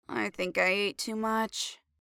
farty1.mp3